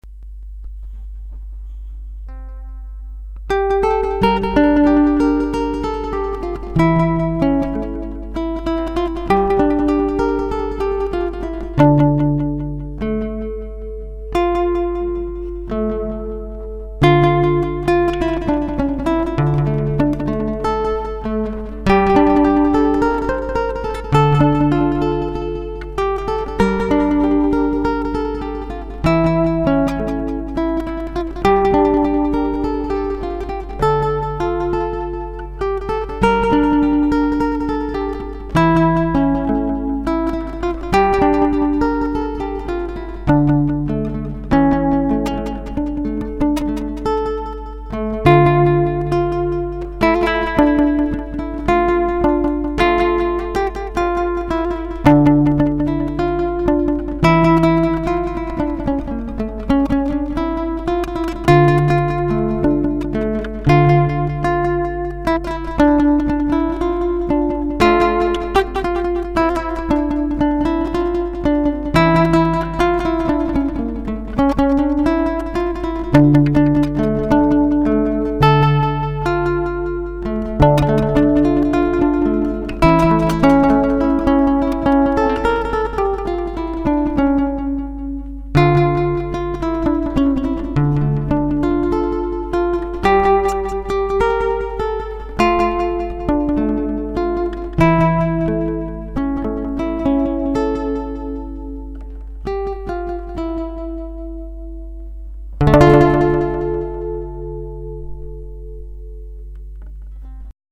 אני גם מנגן פה את השיר בגיטרה.